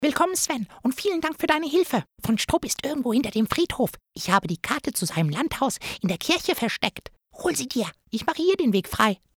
Meine Stimme klingt unverfälscht, jung und sympathisch und ist vielseitig einsetzbar für jugendliche bis männlich-zärtliche Charaktere.
Improvisation. „Die Maus“
Castingaufnahme
Comichaft